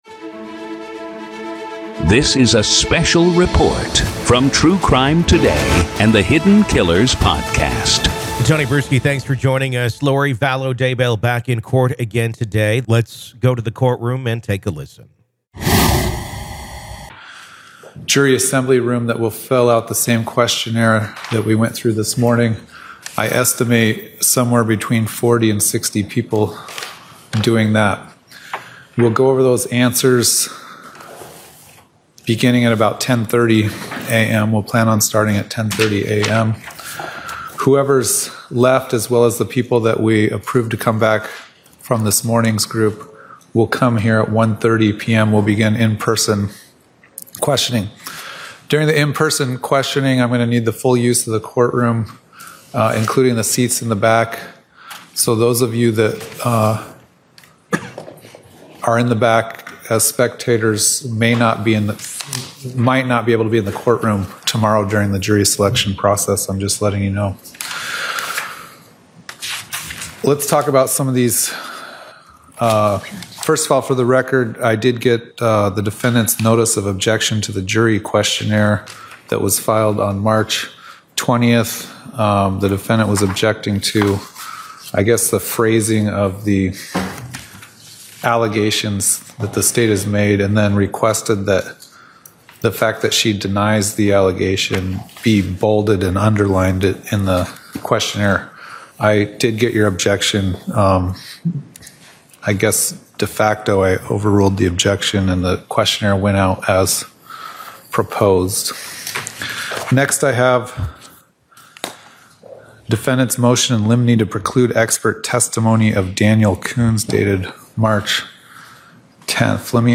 COURT AUDIO: AZ VS LORI DAYBELL DAY 1 JURY SELECTION PART 1
In a heated hearing held Monday afternoon in Arizona, Lori Vallow Daybell passionately argued motions in her ongoing case.